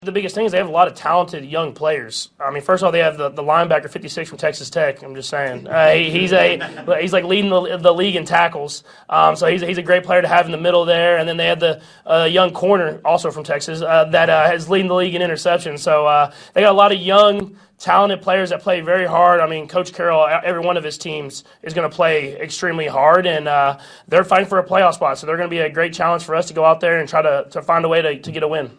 12-24-patrick-mahomes-on-seattle-defense.mp3